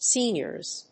発音記号
• / ˈsinjɝz(米国英語)
• / ˈsi:njɜ:z(英国英語)